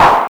Index of /90_sSampleCDs/USB Soundscan vol.11 - Drums Dance & Groove [AKAI] 1CD/Partition B/04-ALL CLAPS
TRA05CLAP.wav